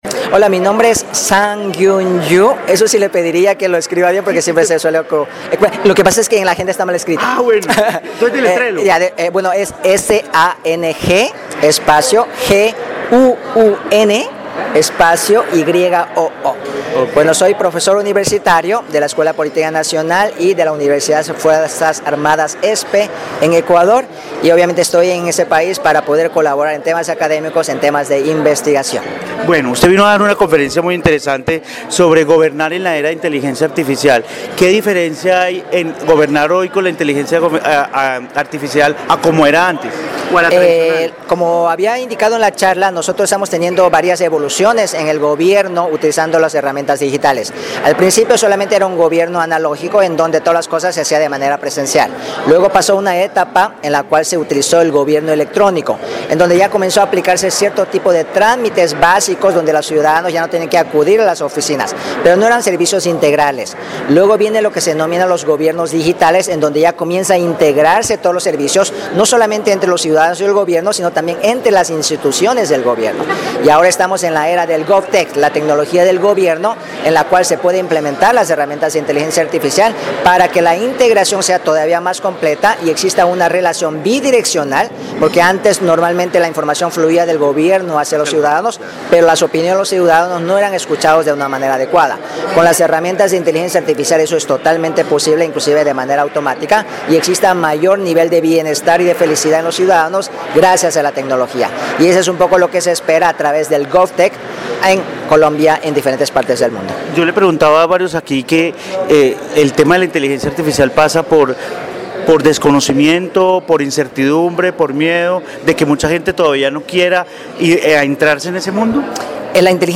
En Armenia se llevó cabo Poder IA, el congreso Internacional de Inteligencia Artificial para la gobernanza en el hotel Mocawa Plaza con la presencia de expertos nacionales e internacionales que durante dos días disertaron sobre el papel de IA en los gobiernos, los estados, la política y las elecciones.